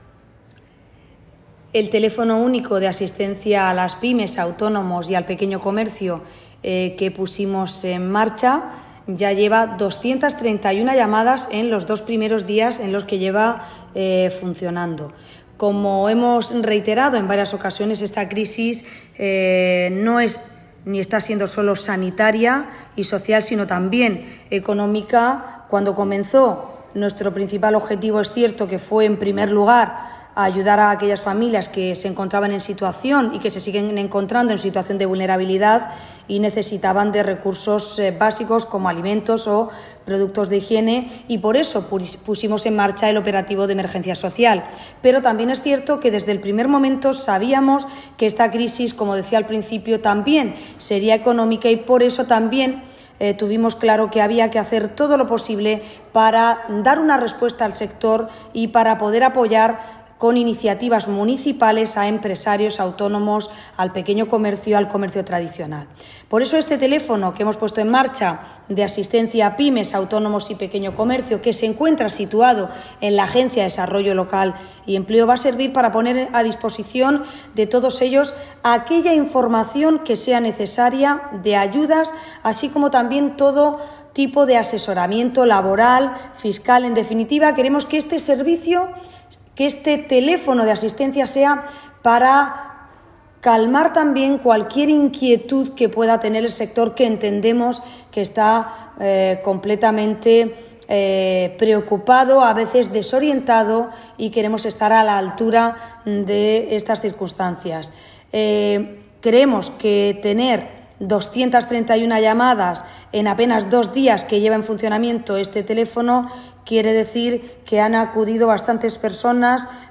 Enlace a Declaraciones de la alcaldesa, Ana Belén Castejón, sobre llamadas recibidas en el Teléfono Único de asistencia a empresas